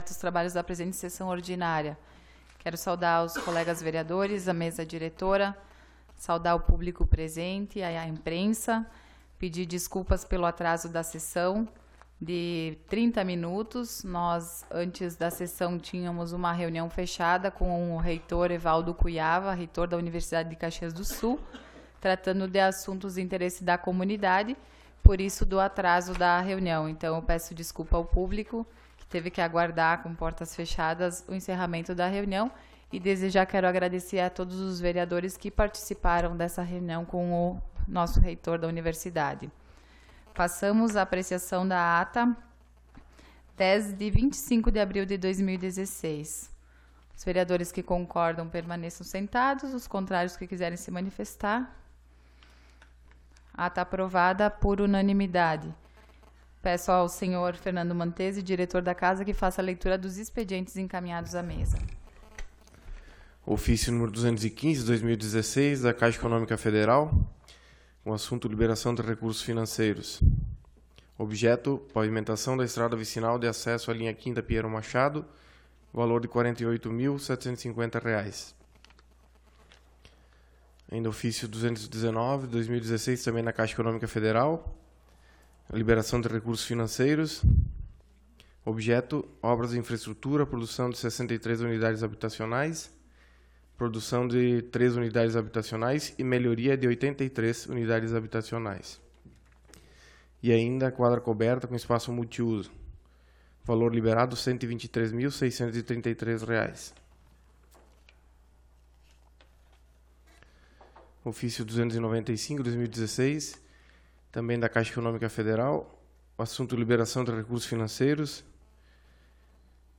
Sessão Ordinária do dia 09 de Maio de 2016